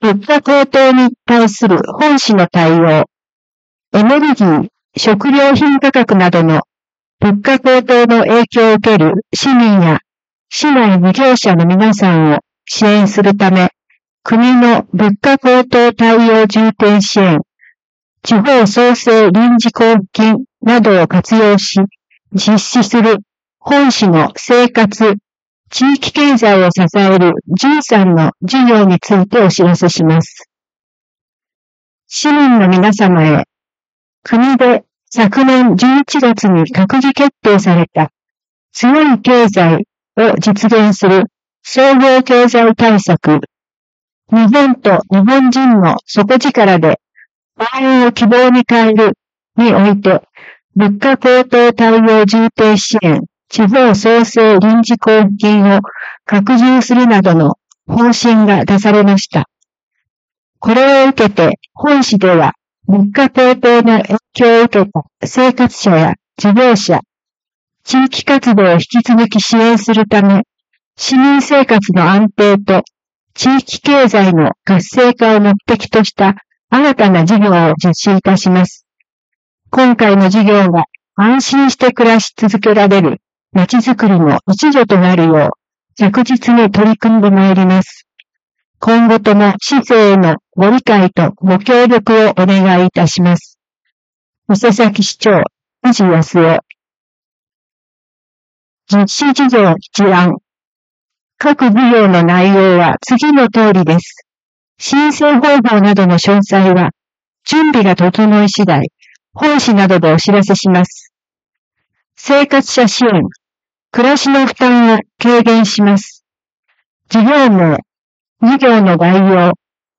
声の広報は目の不自由な人などのために、「広報いせさき」を読み上げたものです。
朗読
伊勢崎朗読奉仕会